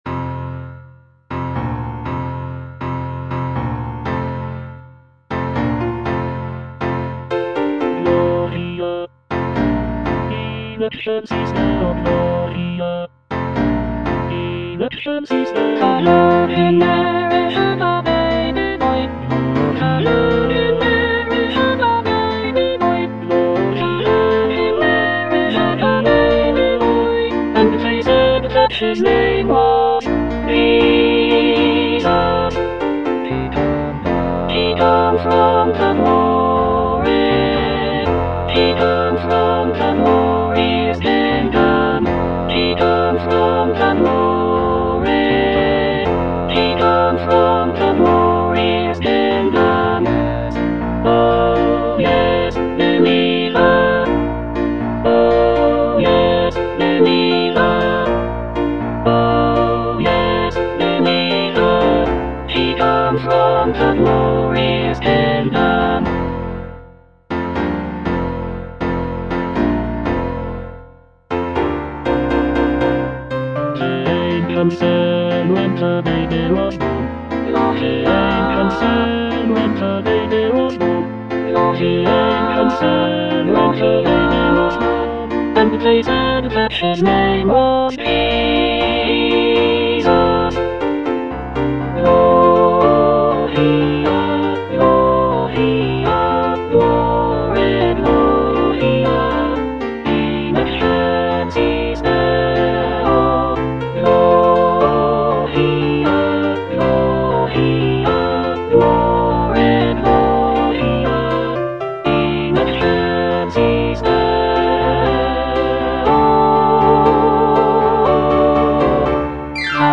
All voices
a vibrant and energetic arrangement
" set to a lively calypso rhythm.